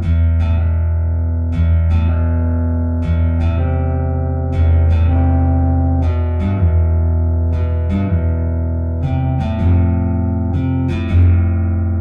Guitar samples
It's more of an Ostinato than chords. Technically a slow appregio?
Two bars per chord.
progression1-160.ogg